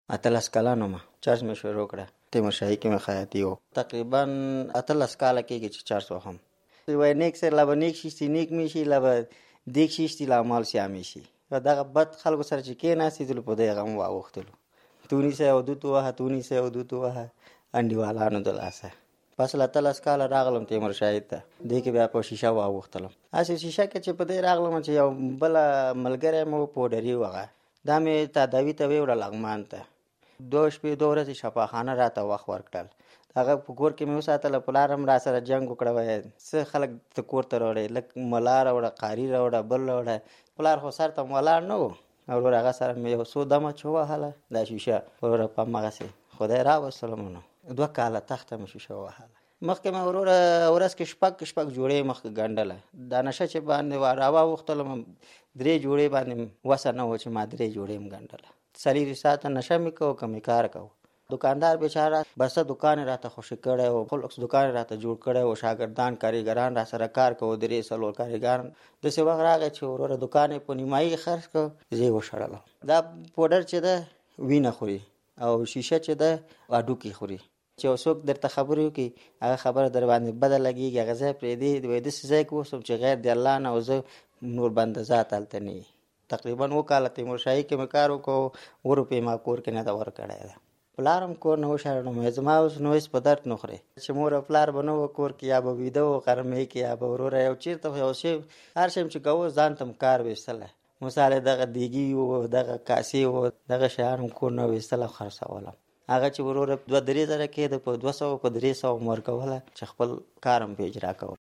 په دې اړه یې د ازادي راډیو له خبریال سره د زړه خواله کړې ده، د لاندې تړوني په کېکاږلو سره یې مرکه اوریدلی شئ: